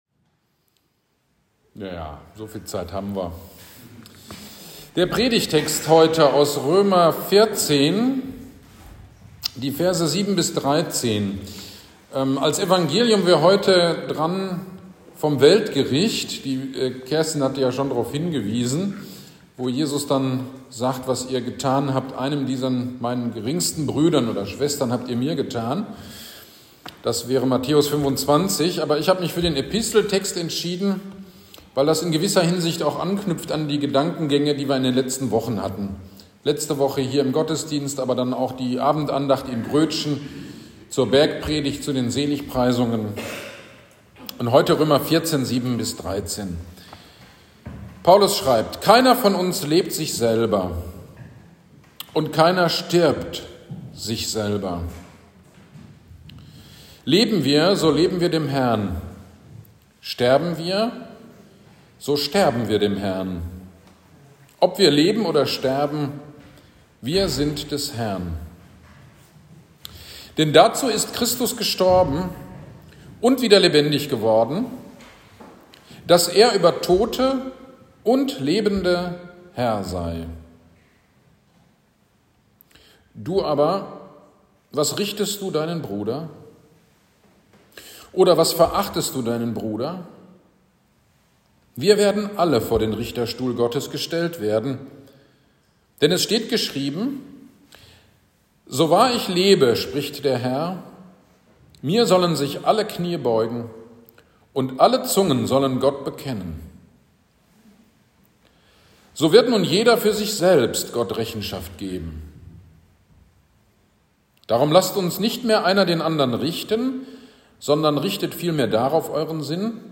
GD am 19.11.2023 Predigt zu Römer 14.7-13 - Kirchgemeinde Pölzig